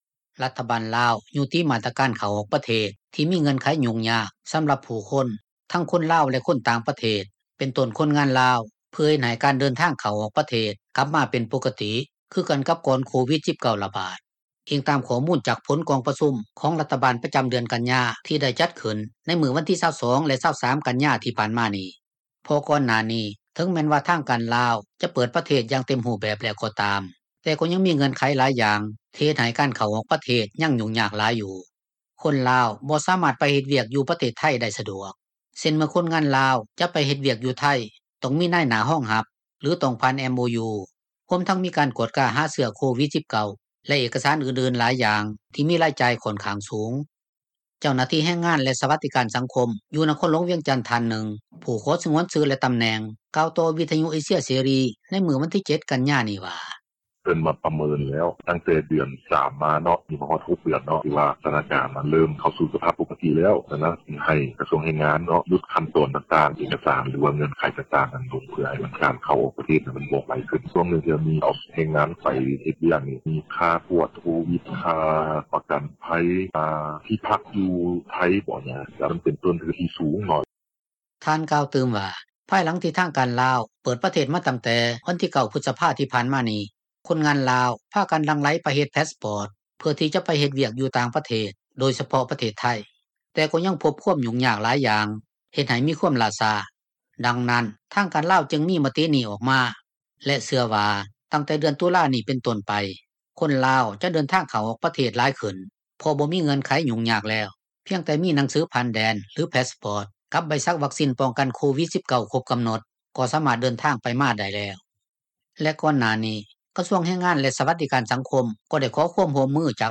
ຄົນງານລາວຜູ້ນຶ່ງ ກໍເວົ້າວ່າ ເປັນເຣື່ອງດີ ທີ່ຈະໄດ້ ເຂົ້າ-ອອກ (ປະເທດ) ລາວໄດ້ສະດວກຂຶ້ນ ຄືມີແຕ່ພາສປອຣ໌ຕ ແລະໃບສັກວັກຊິນ ປ້ອງກັນໂຄວິດ-19 ກໍສາມາດໄປໄທຍໄດ້ແລ້ວ ໂດຍບໍ່ຕ້ອງຜ່ານ ລະບົບ MOU ຫລື ຜ່ານນາຍໜ້າ ທີ່ມີຂໍ້ຫຍຸ້ງຍາກຫລາຍຢ່າງ.